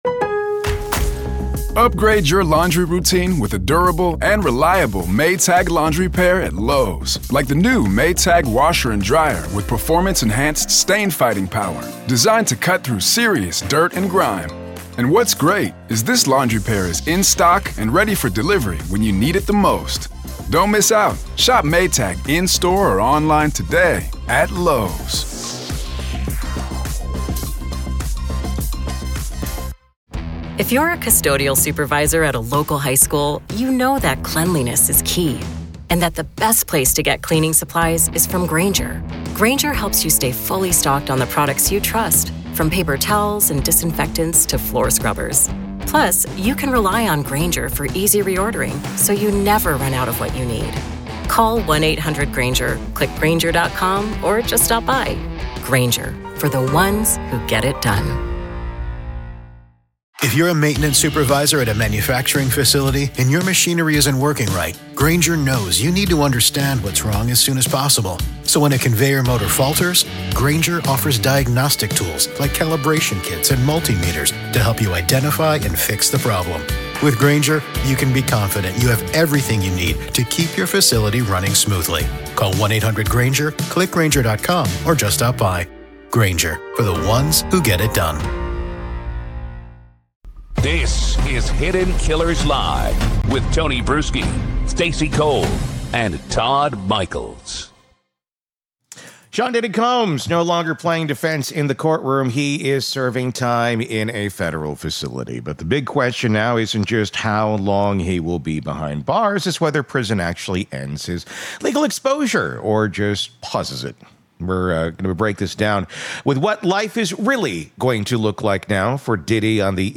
This interview lays bare the next chapter—and how the federal government may still be flipping pages.